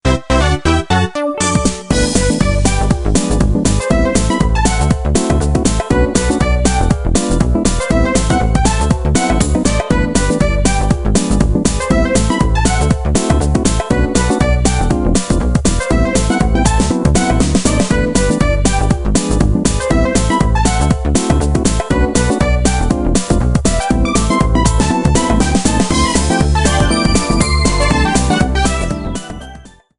minigame theme rearranged